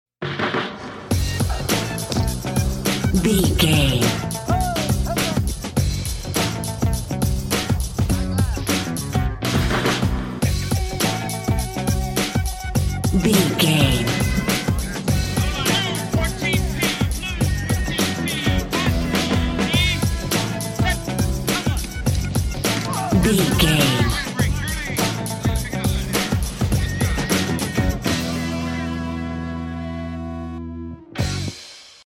Epic / Action
Fast paced
Aeolian/Minor
F#
groovy
energetic
driving
bouncy
bass guitar
drums
vocals
electric guitar
breakbeat
power pop rock
synth lead
synth bass